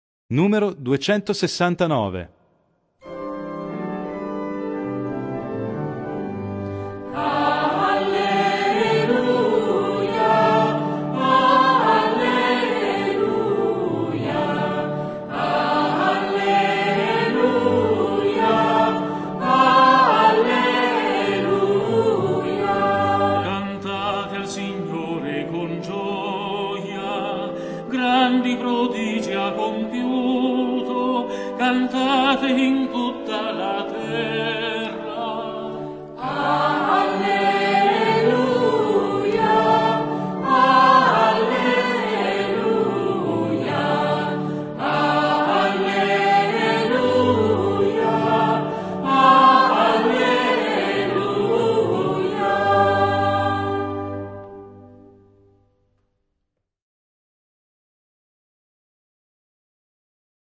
Il canto di avvento prepara, come in un cammino, l'avvicinarsi al Natale: ecco allora che i tempi musicali sono quasi tutti in 3/4 o 6/8, come a mimare quel passo che ci accompagna verso il mistero: canti di meditazione e di incontro con la Parola che salva: non ci sarà più il Gloria, ma daremo spazio al canto della misericordia di Dio, invocando Kyrie, eleison!